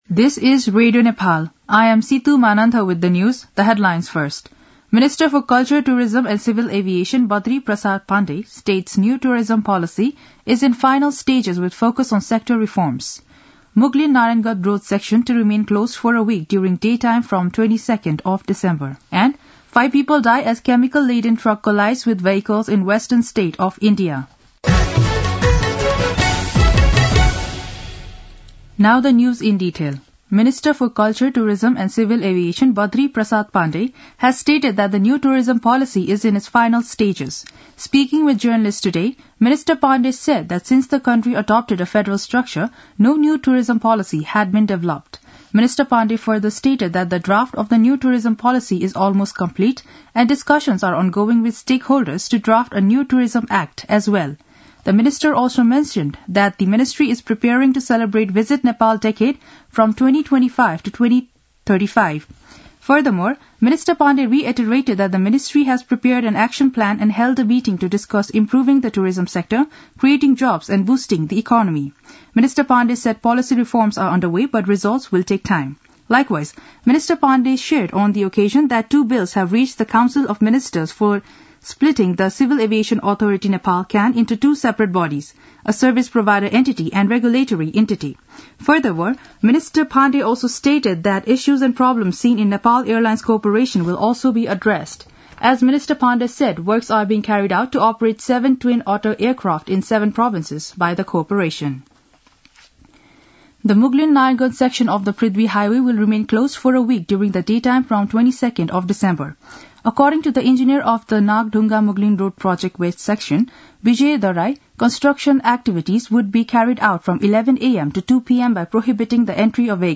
दिउँसो २ बजेको अङ्ग्रेजी समाचार : ६ पुष , २०८१
2-pm-english-news-1-13.mp3